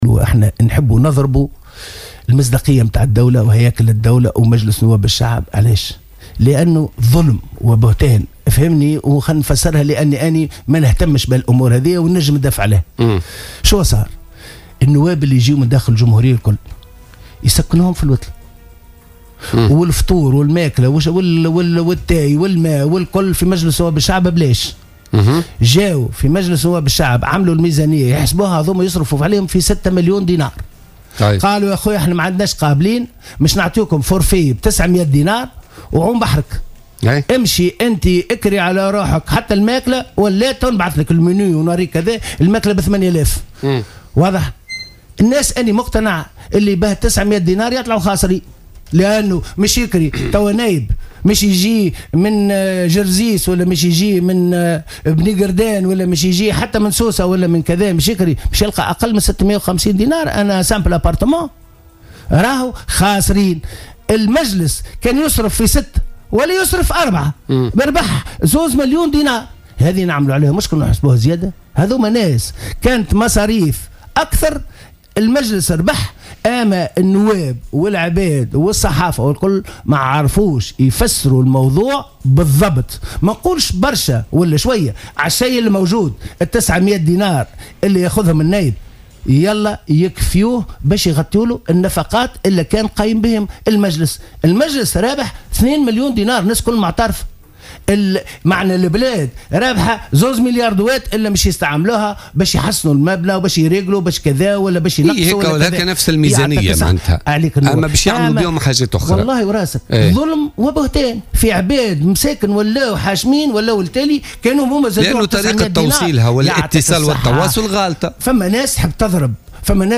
وأكد لـ "الجوهرة أف أم" في برنامج "بوليتيكا" أنه بتخصيص هذه المنحة فقد تم تقليص نحو 2 مليون دينار من نفقات المجلس.